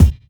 Sharp Bass Drum Sound E Key 121.wav
Royality free kickdrum tuned to the E note. Loudest frequency: 363Hz
sharp-bass-drum-sound-e-key-121-6dF.ogg